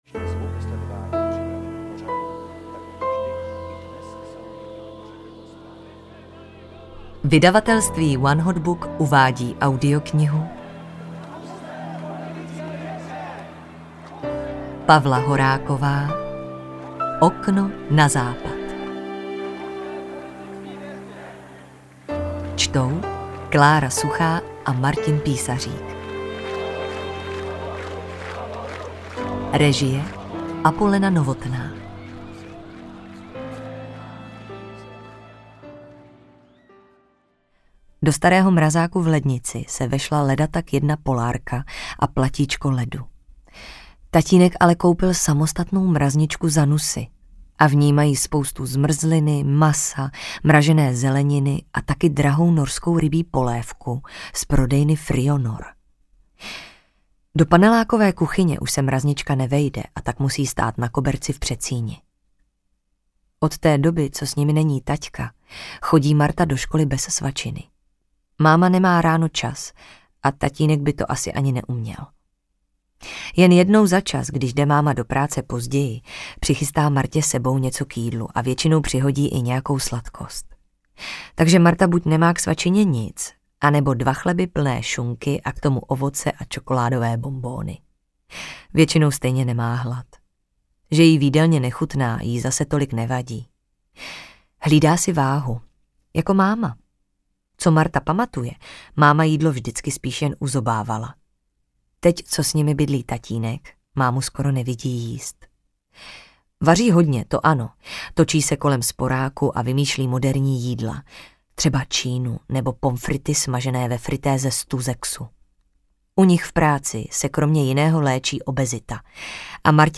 Okno na západ audiokniha
Ukázka z knihy